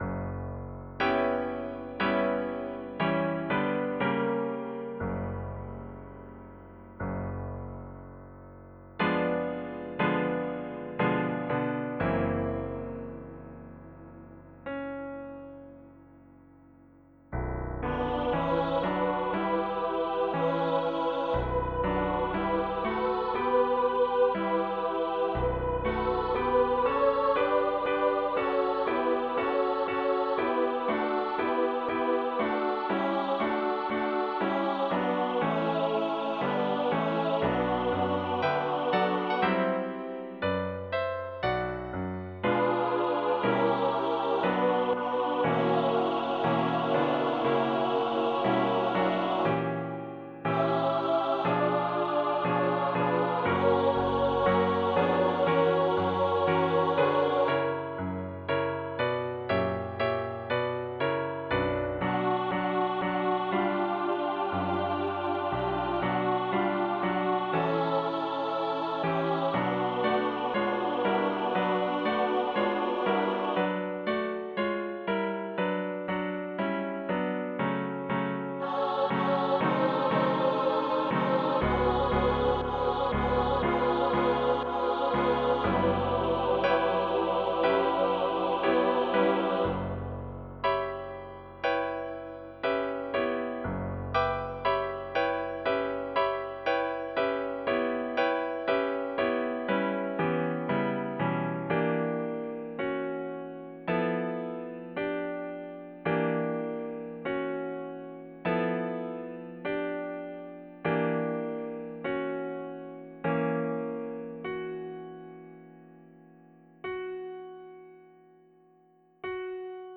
This is an Easter anthem arranged for women's (SSAA) choir from a song by David M. Guion and words from ancient Irish poetry.
A 2 page voice parts score and a computer generated sound file are also included.